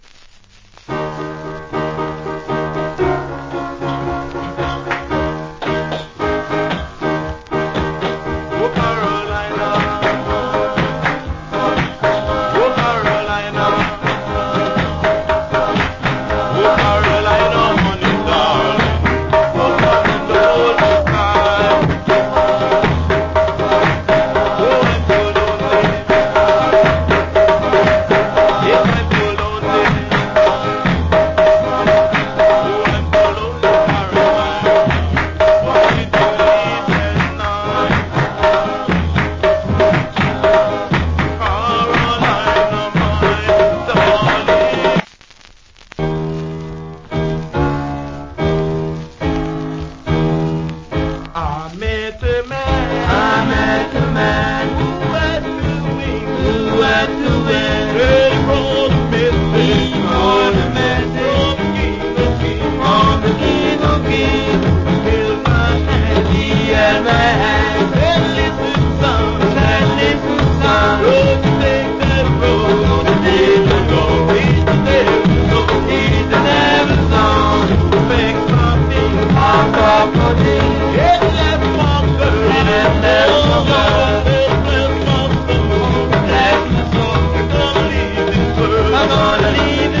category Ska
Nyabinghi Vocal. / Good Vocal.